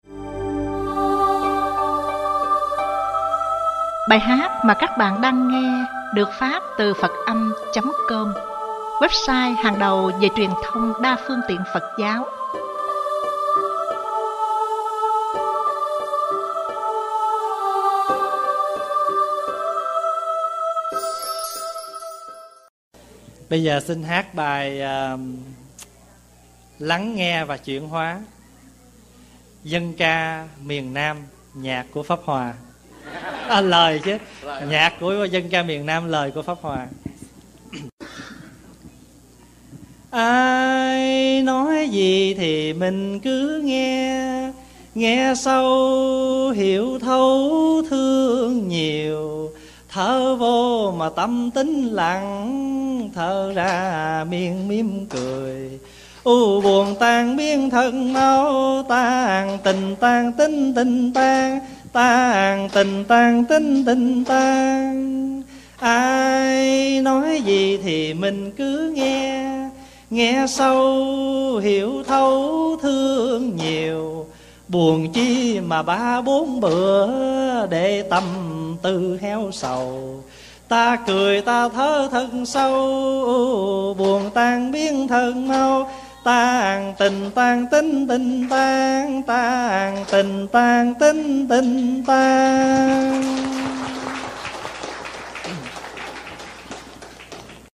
Ca nhạc: Lắng Nghe và Chuyển Hoá - Thích Pháp Hòa